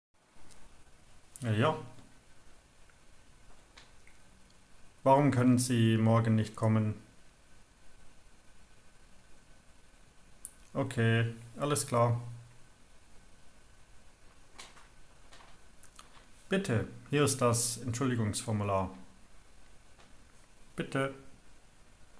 Text-Karaoke! Sprechen Sie mit dem Lehrer